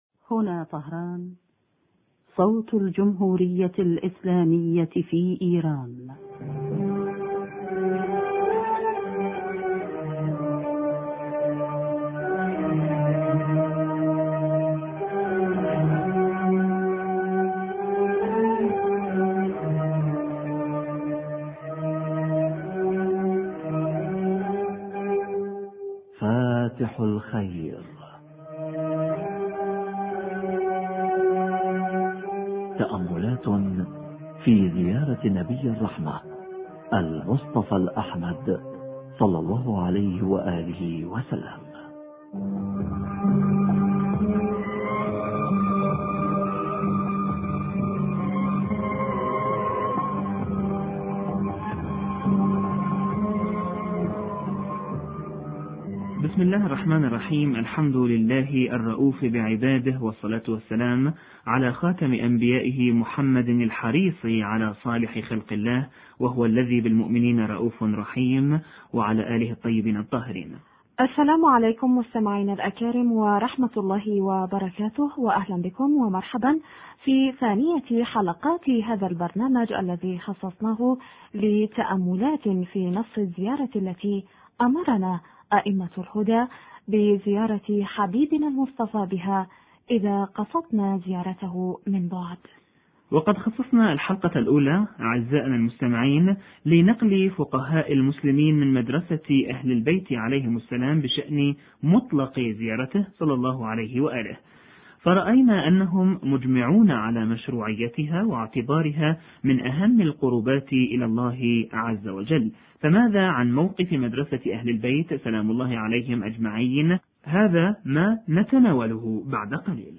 اهتمام ائمة الهدى(ع) باستمرار زيارة قبر النبي(ص) حوار